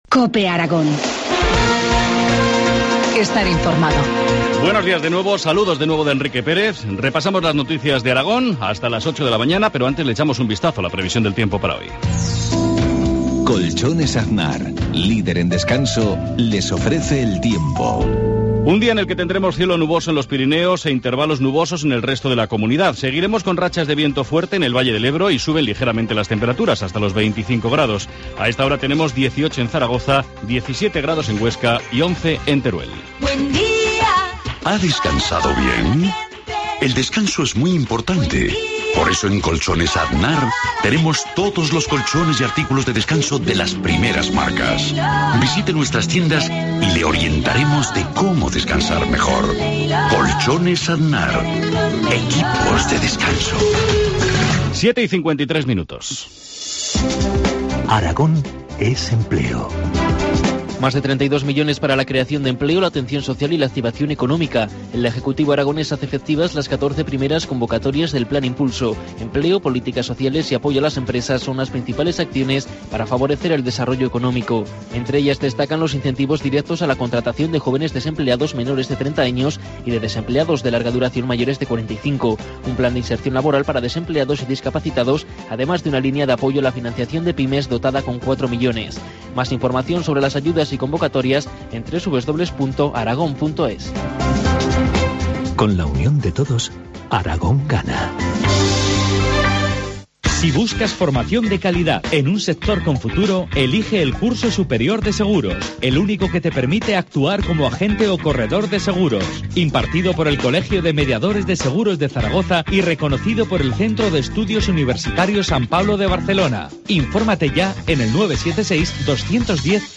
Informativo matinal, jueves 19 de septiembre, 7.53 horas